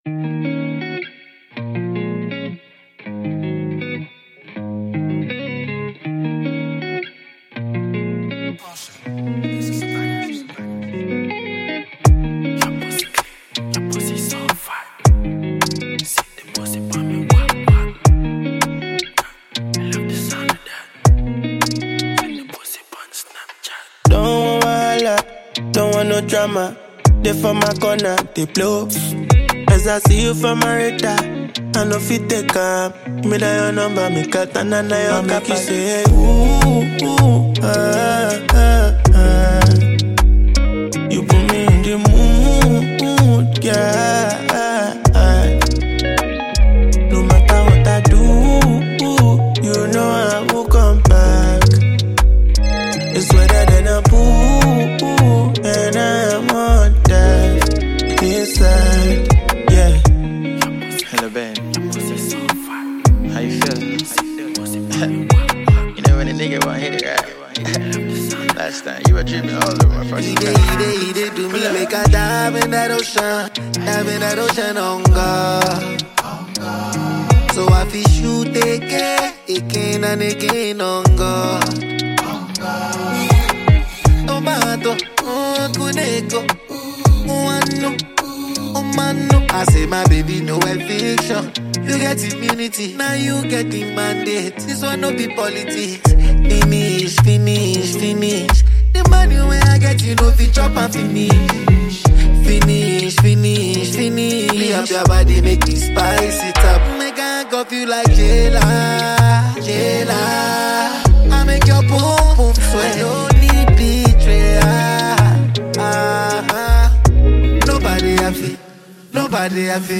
Veteran fast-rising singer